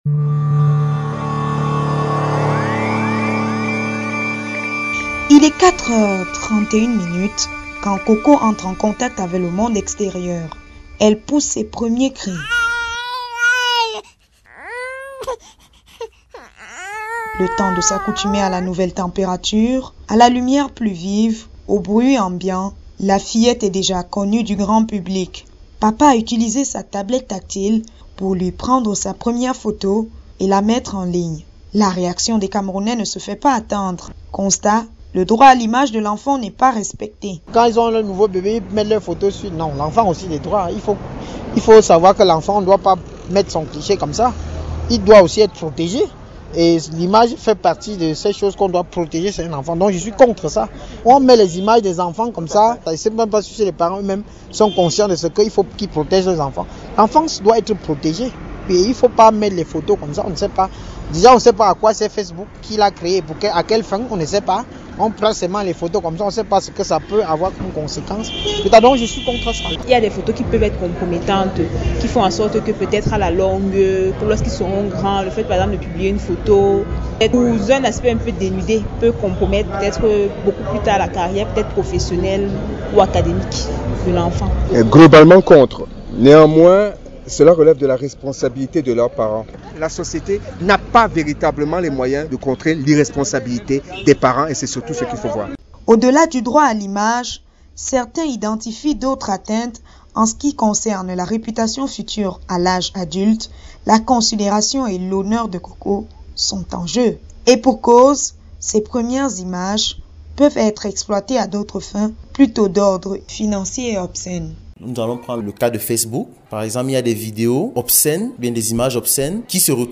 Ne porte-t-on pas atteinte pas à leur droits fondamentaux en voulant les mettre au devant de la scène en âge mineur? Tentative de réponse dans ce webreportage audio: